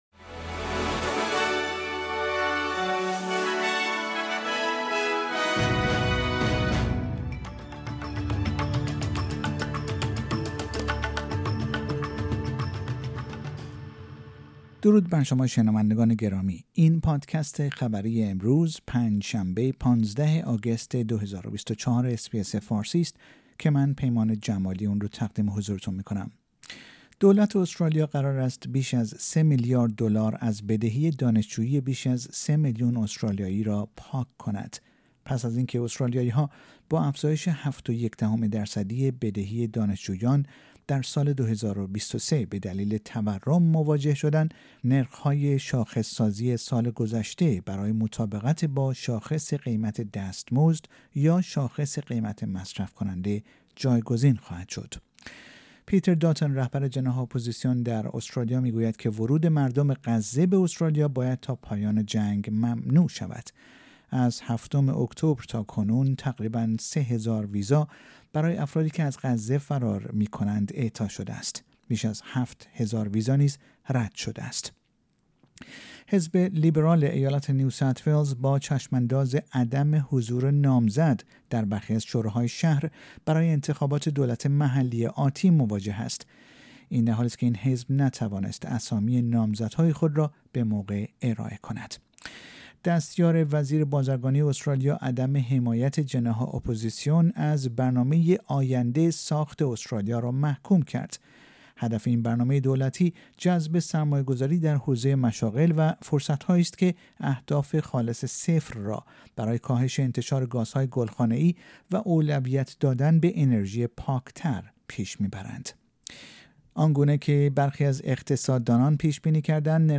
در این پادکست خبری مهمترین اخبار استرالیا در روز پنج شنبه ۱۵ آگوست ۲۰۲۴ ارائه شده است.